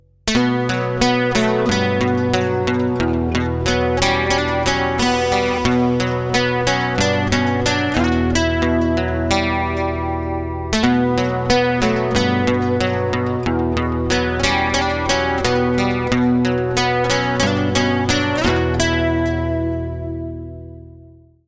• Качество: 204, Stereo
гитара
без слов
инструментальные
Короткий рингтон, в цикле звучит хорошо.